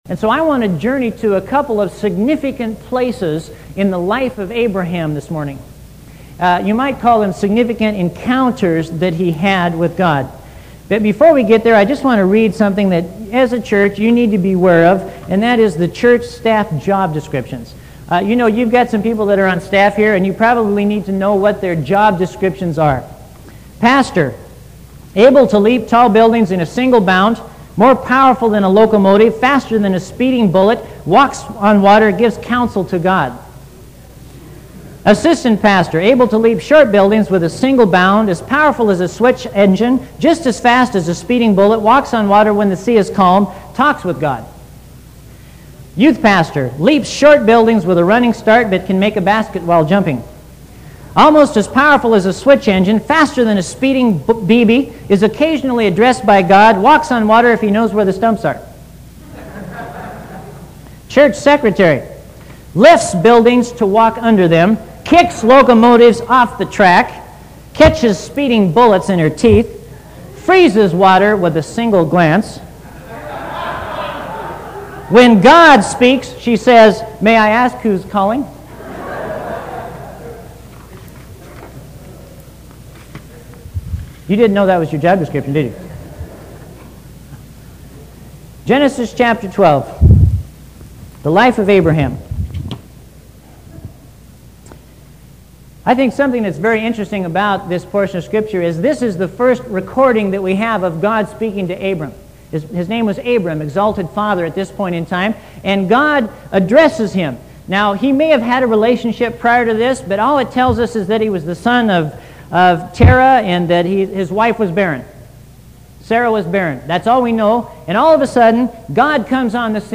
You must have Windows Media Player or a program that can listen to MP3 files to listen to these sermons.